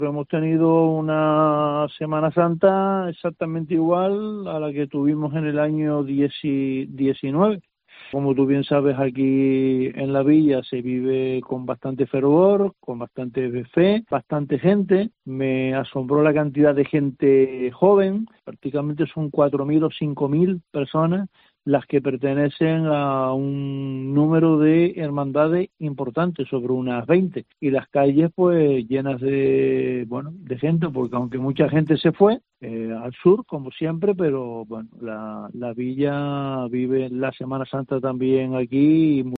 En los micrófonos de Mediodía COPE, el alcalde Francisco Linares destaca que las celebraciones hayan sido casi "exactamente igual" que en 2019.